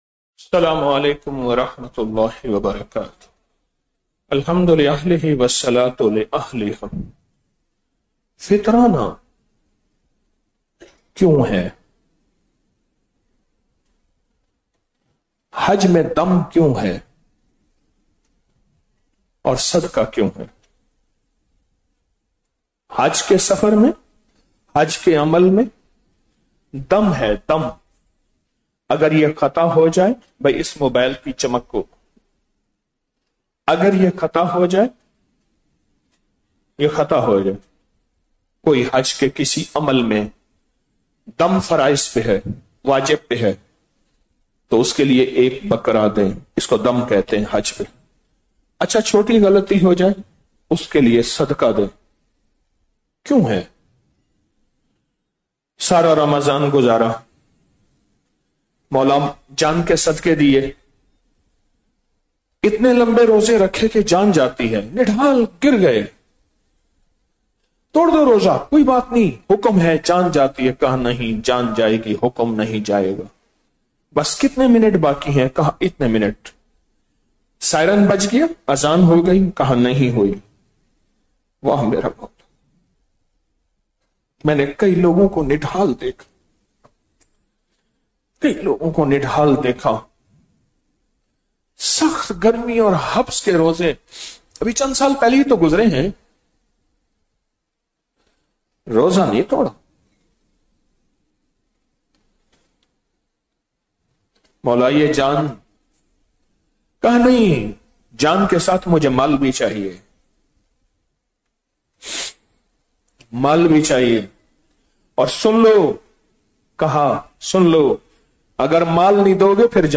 21 رمضان المبارک بعد نماز تراویح - 10 مارچ 2026ء